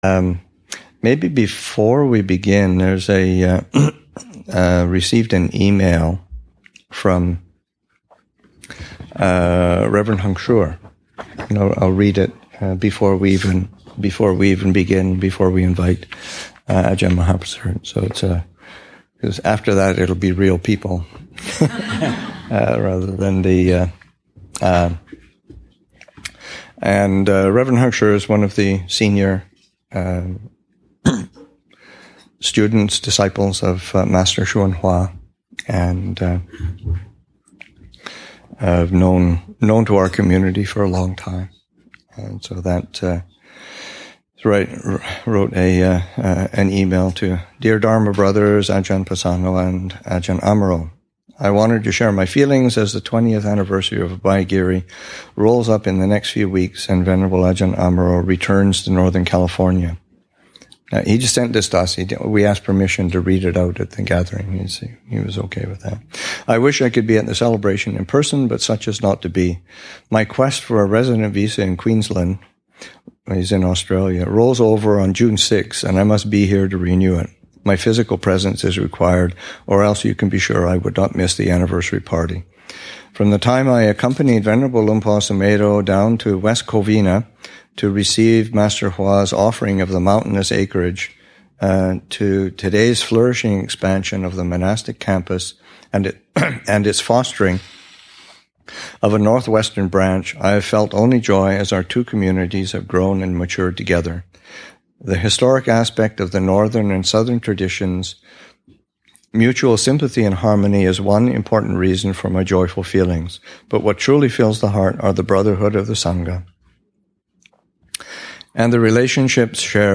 Abhayagiri's 20th Anniversary, Session 2 – Jun. 4, 2016
3. Reading: Reflections from Reverend Heng Sure.